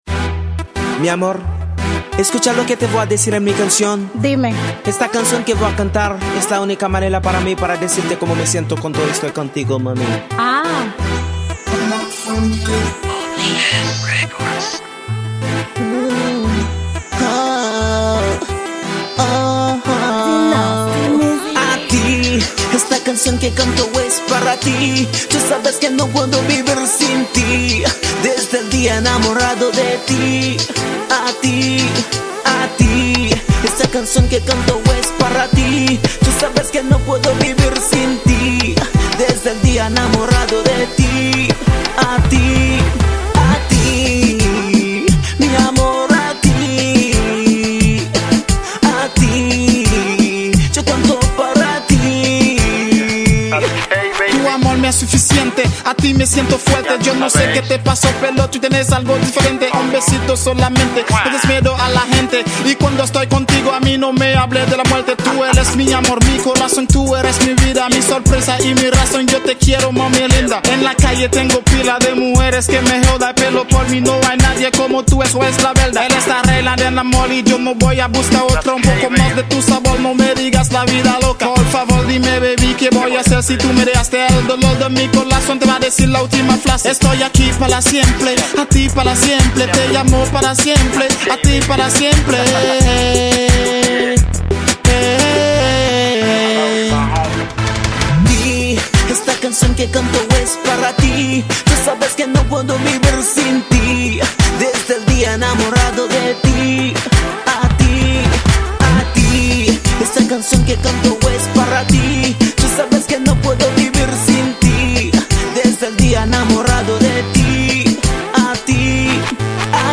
Genre: WORLD